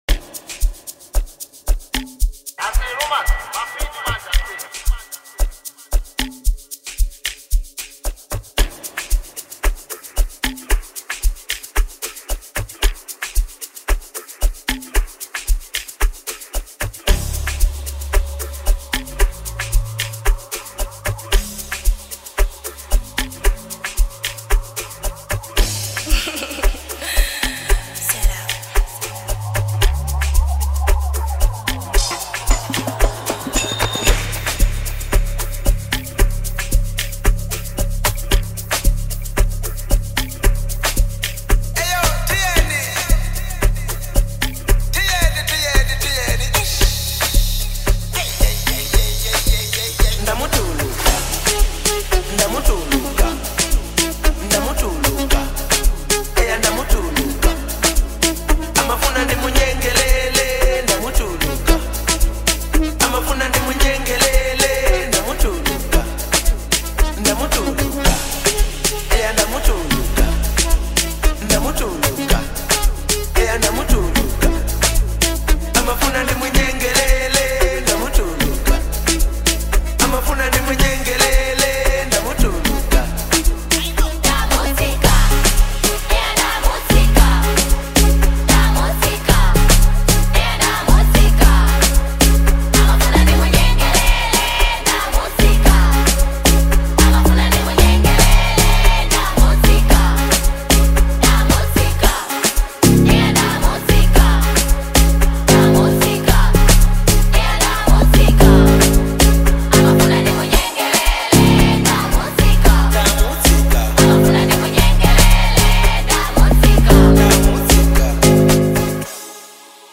Genre : Amapiano